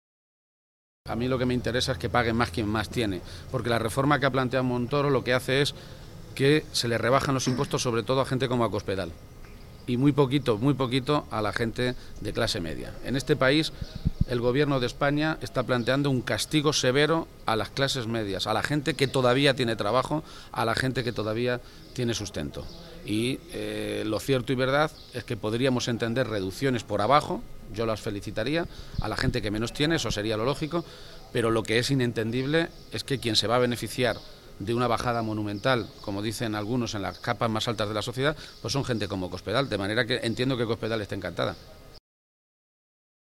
En otro orden de cosas, y también a preguntas de los medios de comunicación, Emiliano García-Page se ha referido a la reforma fiscal planteada por el Gobierno de España.
Cortes de audio de la rueda de prensa